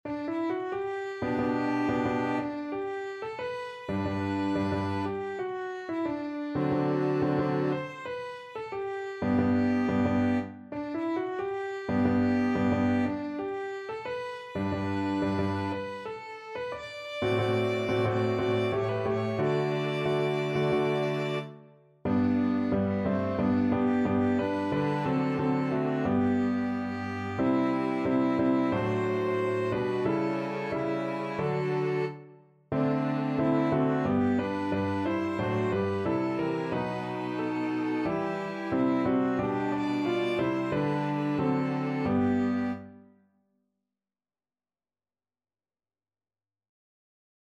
Violin
Clarinet
Cello
4/4 (View more 4/4 Music)